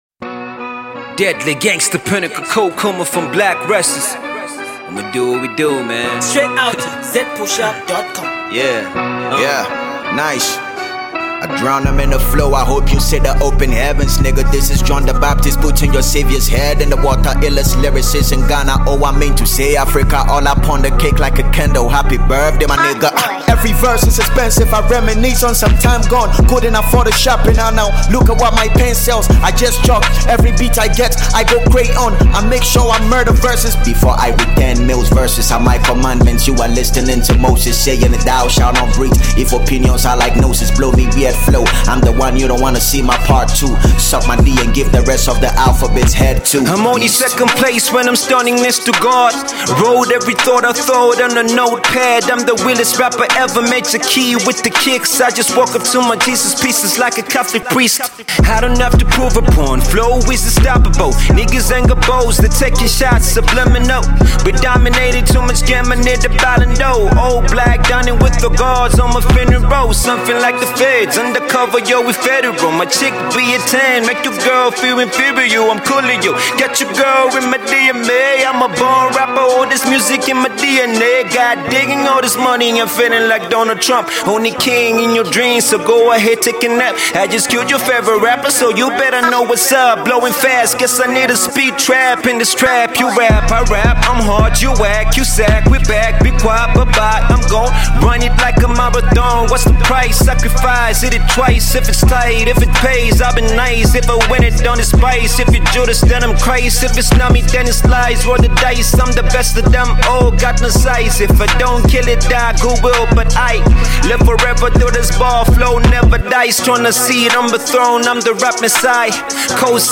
Hip Hop Joint